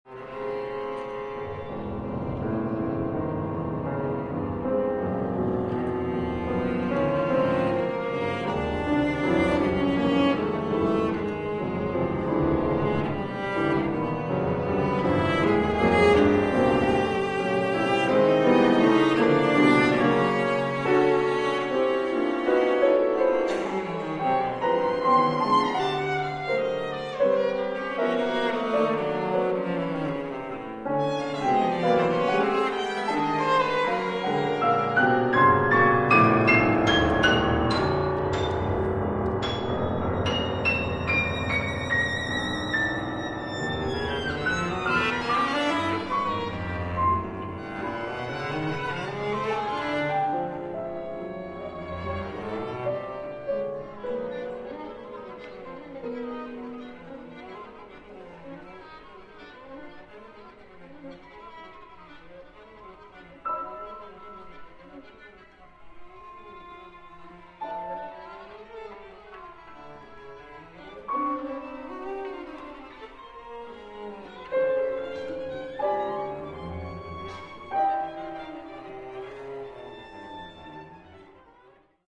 Instrumentation: violin, cello, piano